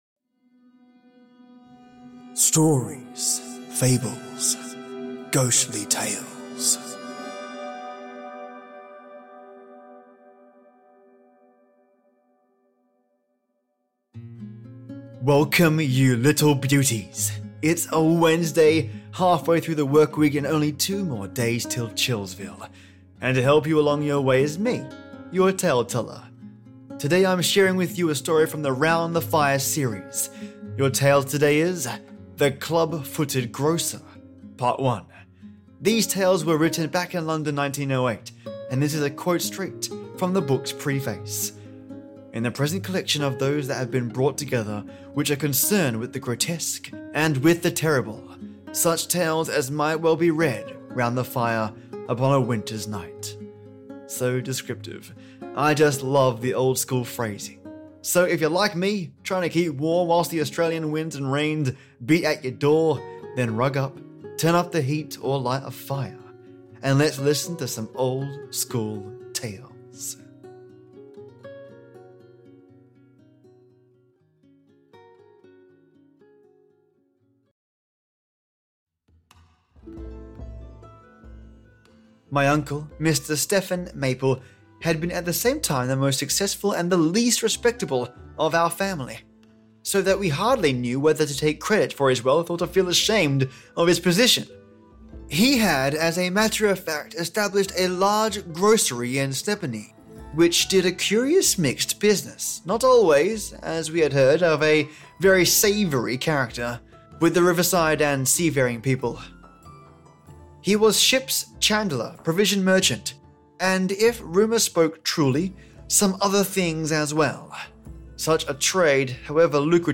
And to help you along your way is me, your Tale Teller. Today I’m sharing with you a story from the “Round the Fire” series – your tale The Club Footed Grocer – Part 1. These tales were written back in London 1908, and this is a quote straight from the book’s preface: In the present collection [of] those [that] have been brought together which are concerned with the grotesque and with the terrible—such tales as might well be read “round the fire” upon a winter’s night.